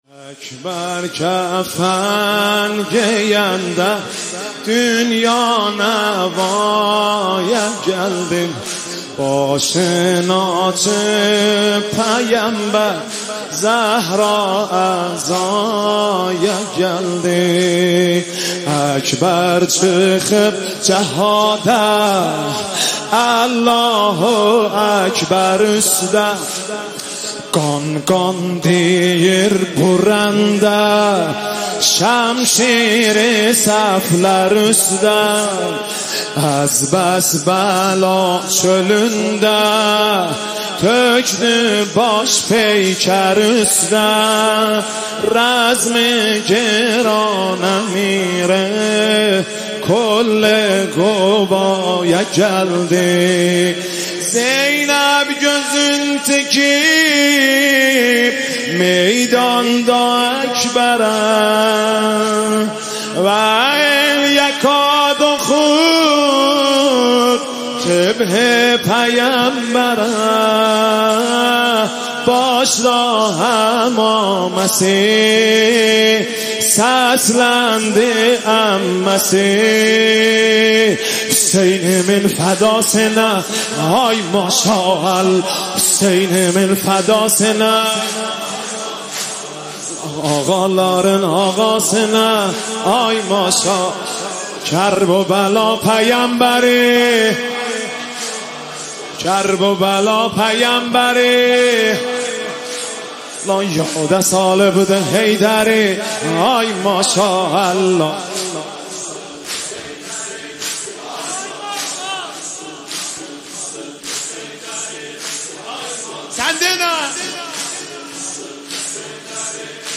نوحه ترکی
با نوای دلنشین